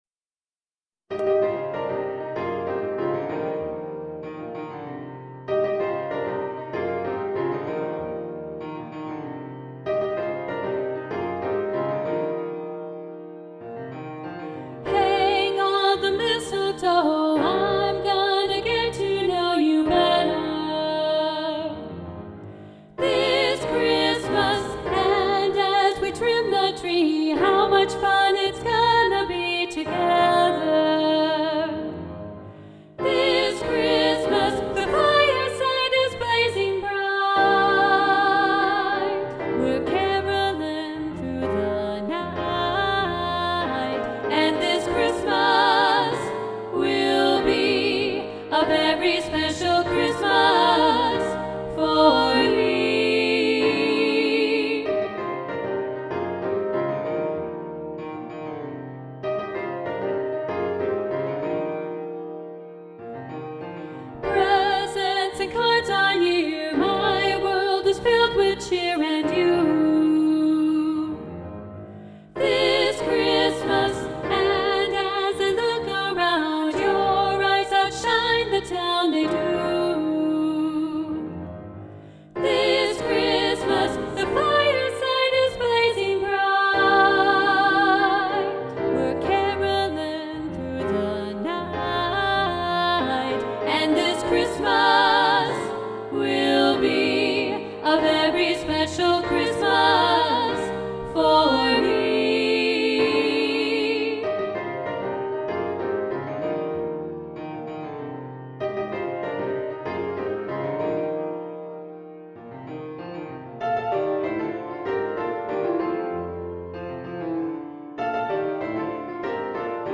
This Christmas SSA – Balanced Voices – arr. Roger Emerson